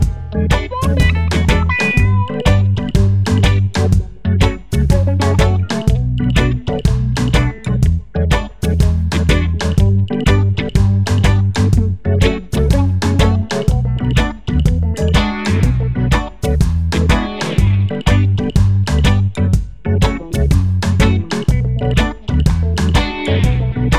no Backing Vocals Reggae 3:32 Buy £1.50